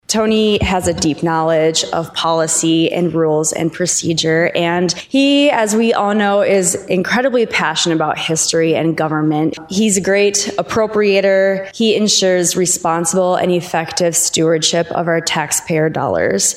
During the House debate, lawmakers of both political parties expressed support for Venhuizen’s nomination. One of those was Democratic House Minority Leader Erin Healy from Sioux Falls. She praised his experience and knowledge of state government.